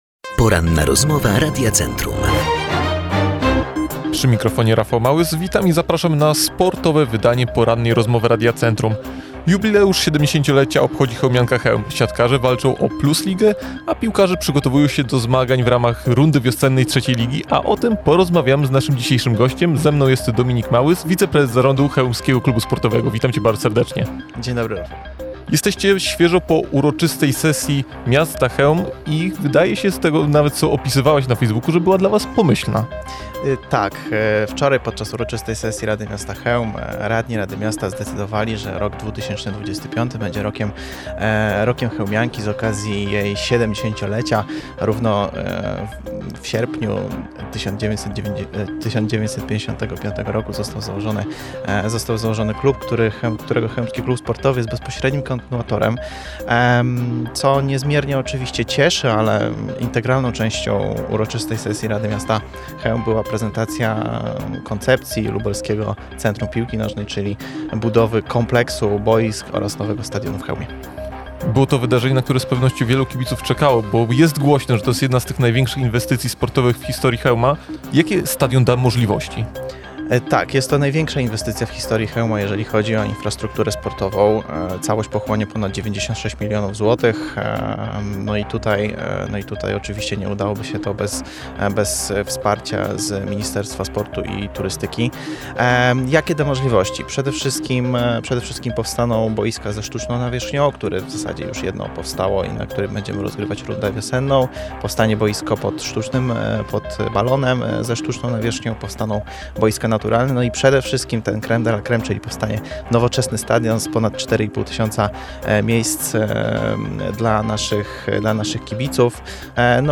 ROZMOWA-1.mp3